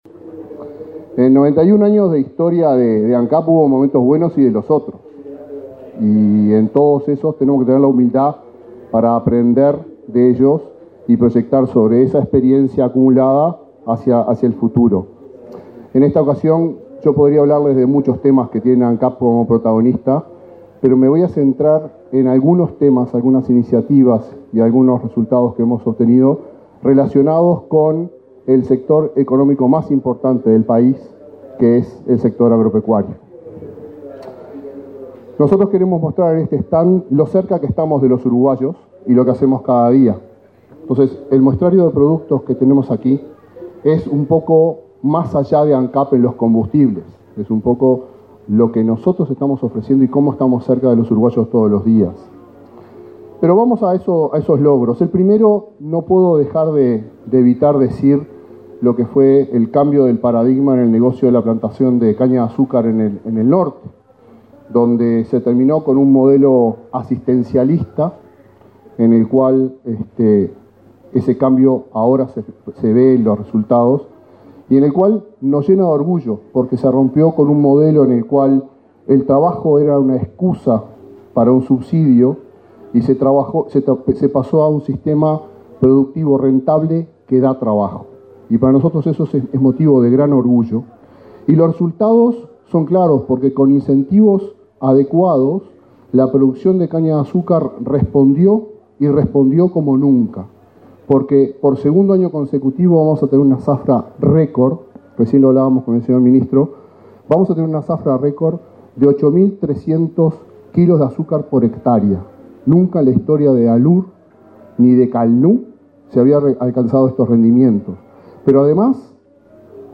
Palabras de autoridades en inauguración de stand de Ancap
Palabras de autoridades en inauguración de stand de Ancap 12/09/2022 Compartir Facebook X Copiar enlace WhatsApp LinkedIn El presidente de Ancap, Alejandro Stipanicic, y el ministro de Ganadería, Fernando Mattos, participaron en la inauguración del stand de la empresa estatal en la Expo Prado.